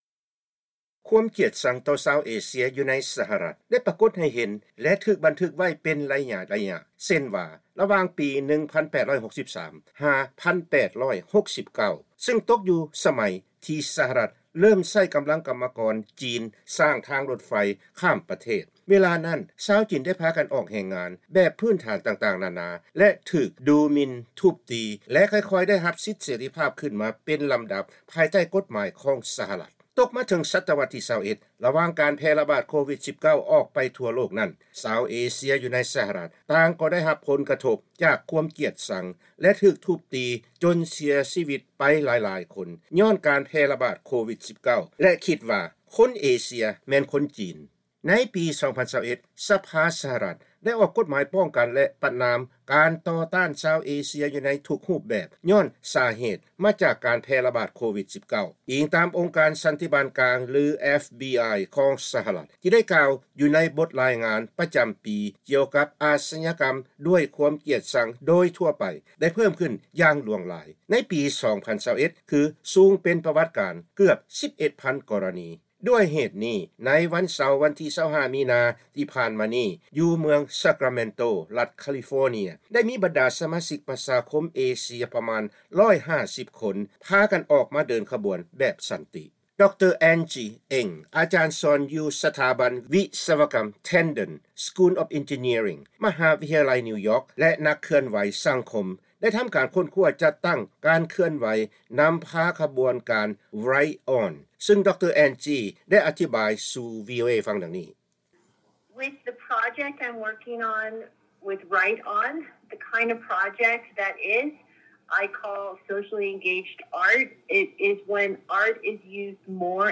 ເຮົາຈະພາທ່ານຟັງການໂອ້ລົມກັບ ບັນດານັກເຄື່ອນໄຫວຊາວເອເຊຍ